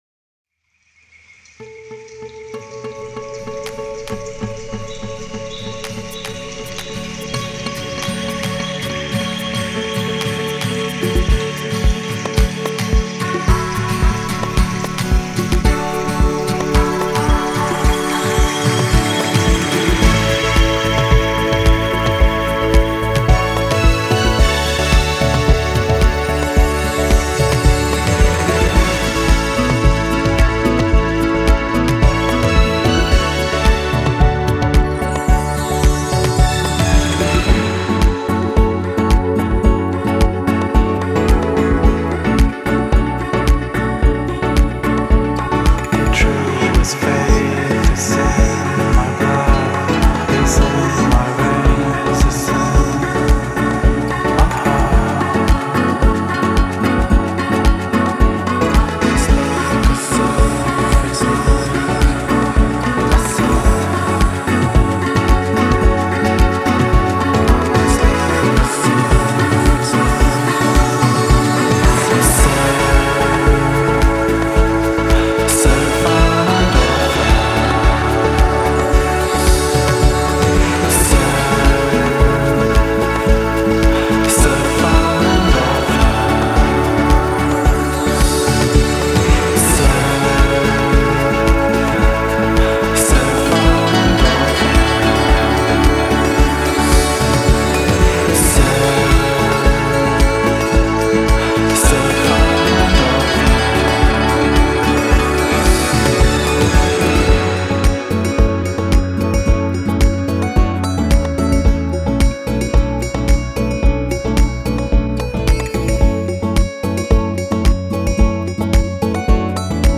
side project elettronico
liquido e solare